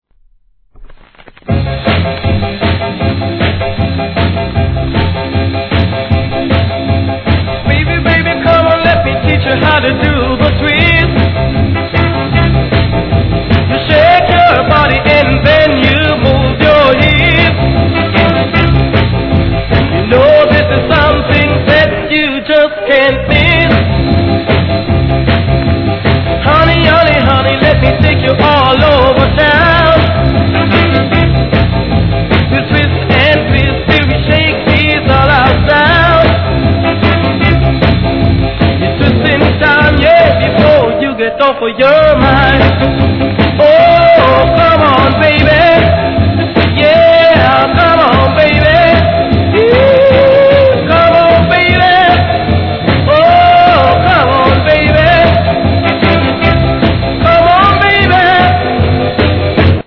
光沢ありませんが目立った傷は無く音圧もあります
REGGAE